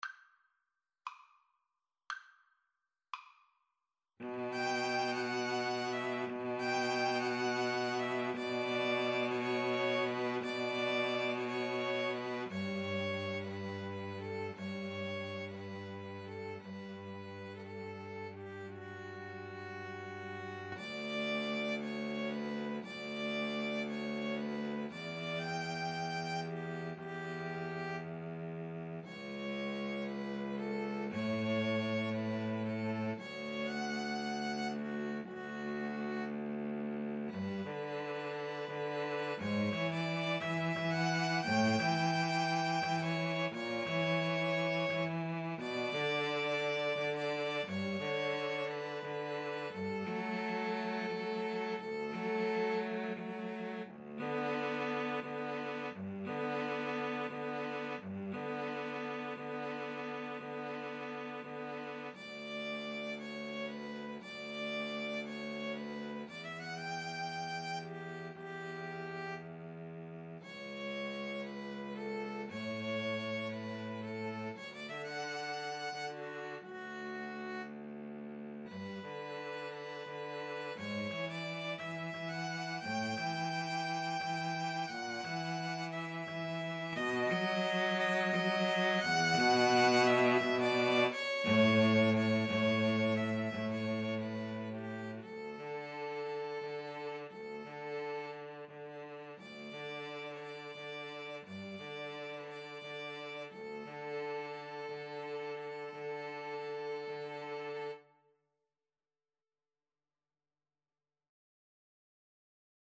6/8 (View more 6/8 Music)
Classical (View more Classical 2-Violins-Cello Music)